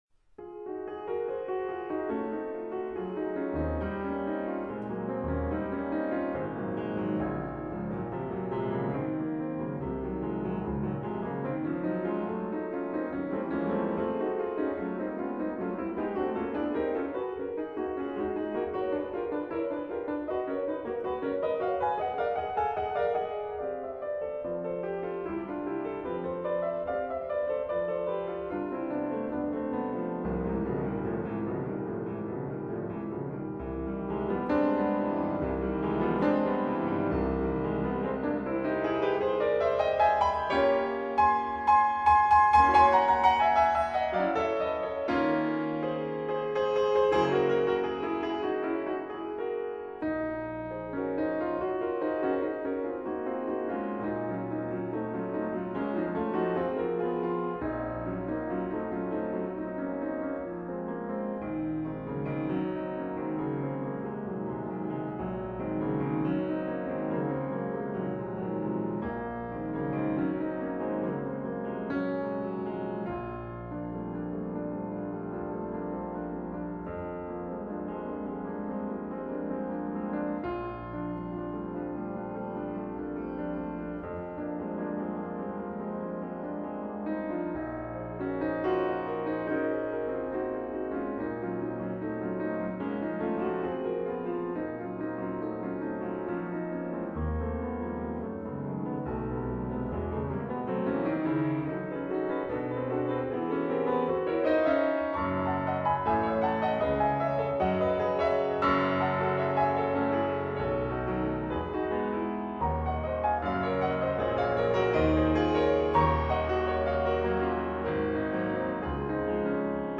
Claude Debussy – Douze Études pour piano